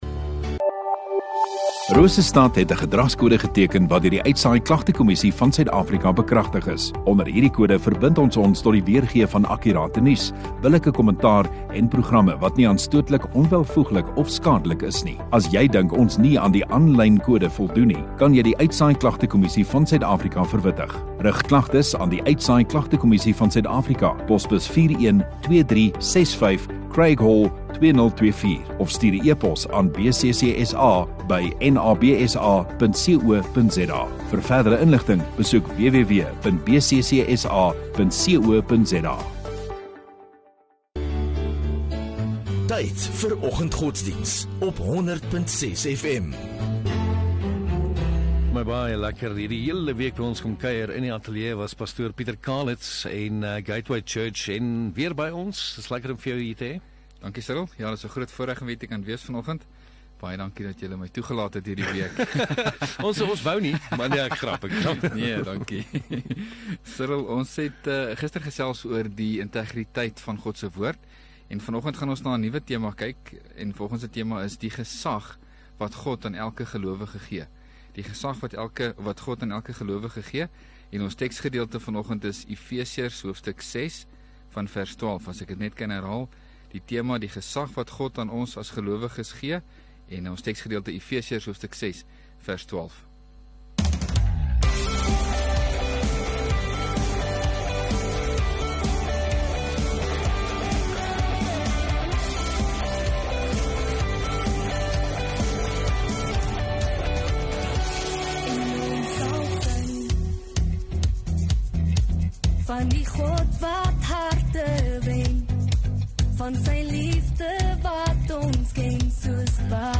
8 Mar Vrydag Oggenddiens